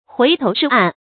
huí tóu shì àn
回头是岸发音
成语注音ㄏㄨㄟˊ ㄊㄡˊ ㄕㄧˋ ㄢˋ